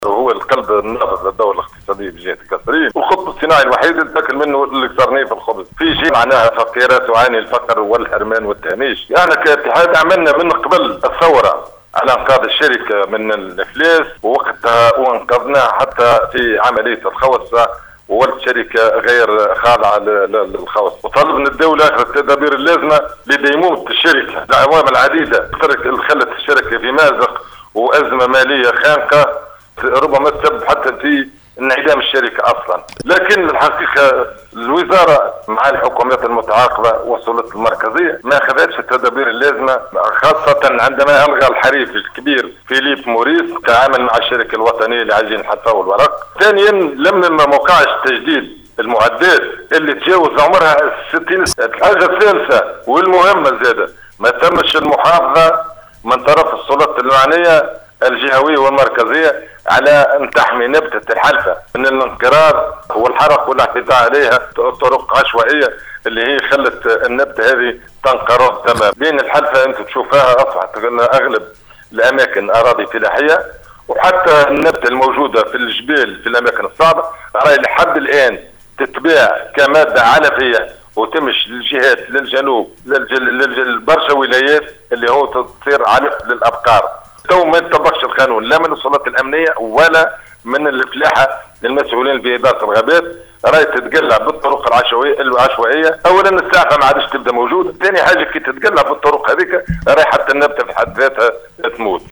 في تصريح لإذاعة السيليوم أف أم